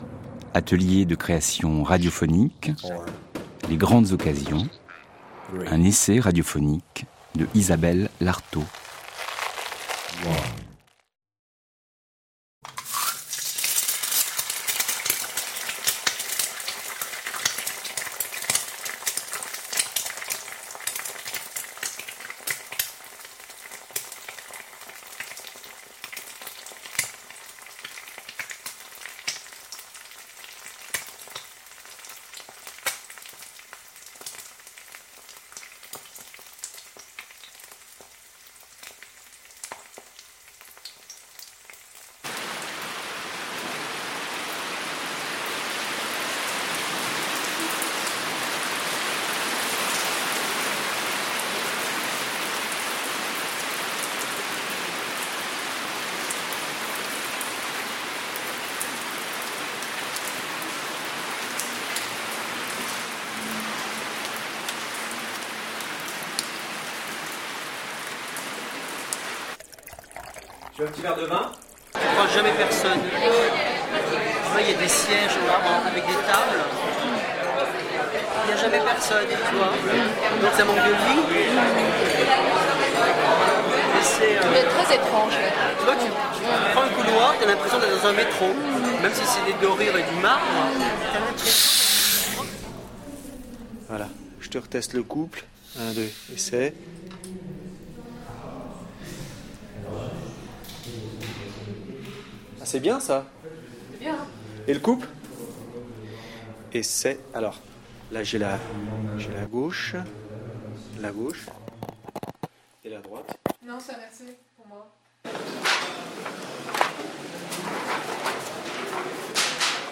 poésie
pour l’atelier de création radiophonique de france culture (diffusé le 23 mars 2008)